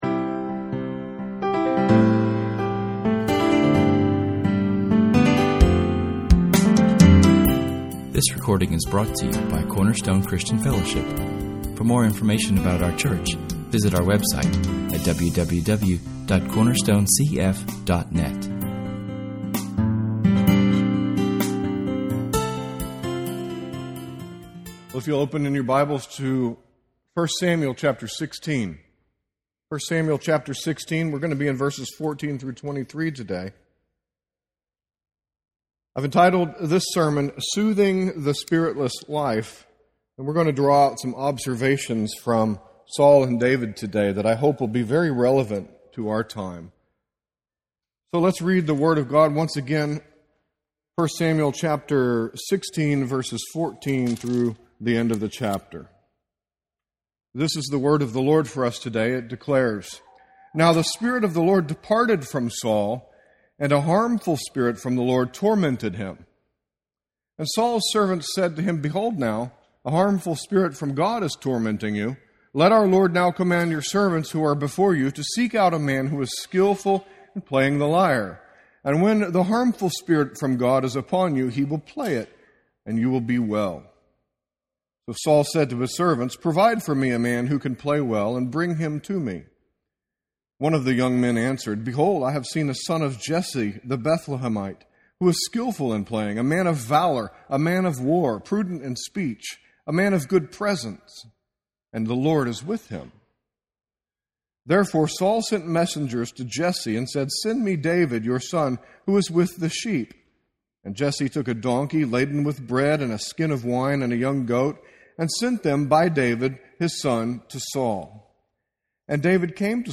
Sermon_2016-09-04.mp3